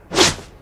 Whoosh.wav